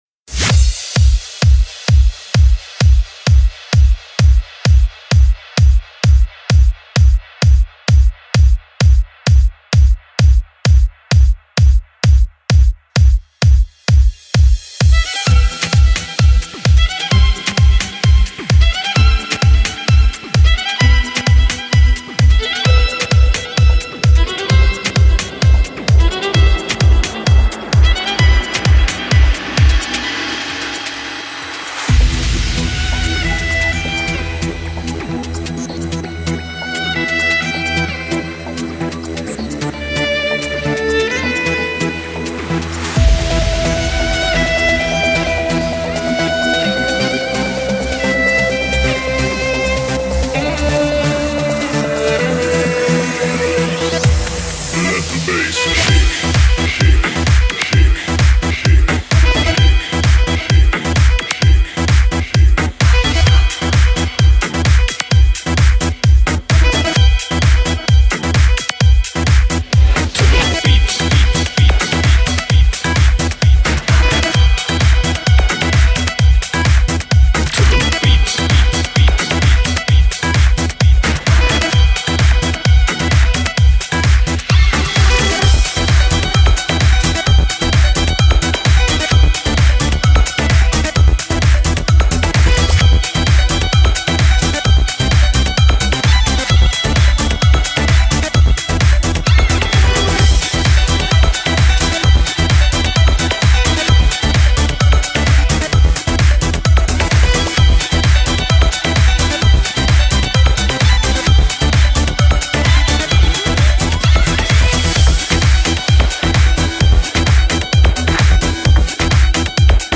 Стиль: Club House